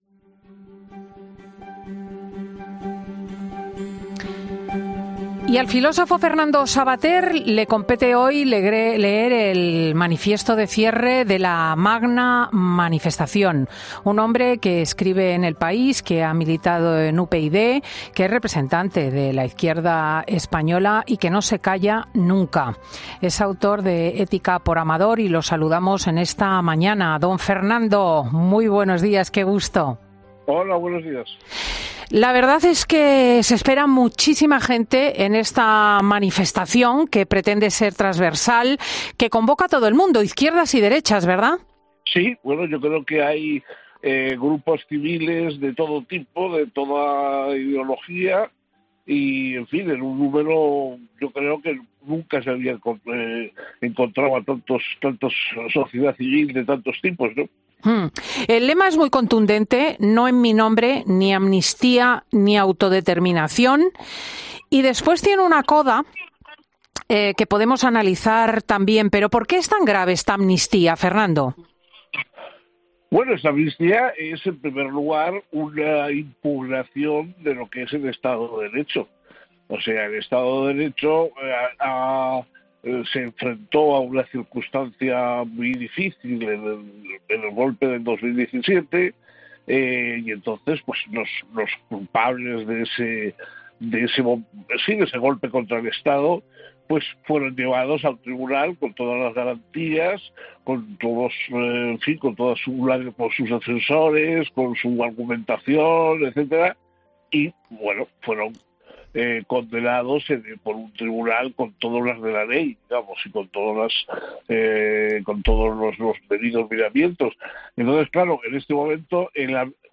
El filósofo y escritor ha pasado por 'Fin de Semana', horas antes de que comience la manifestación en Madrid, para explicar las claves de la misma